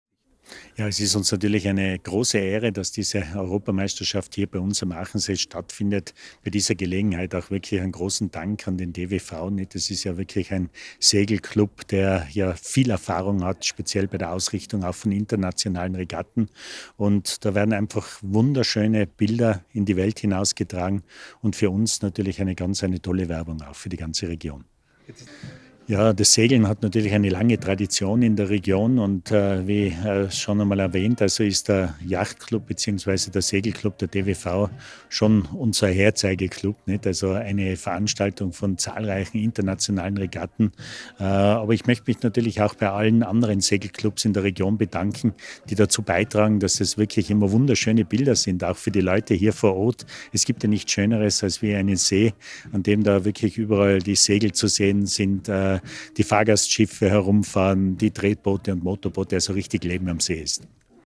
Spektakuläre Drohnenbilder und Boot-zu-Boot-Aufnahmen von der Europameisterschaft im Tornadosegeln 2023 am Achensee.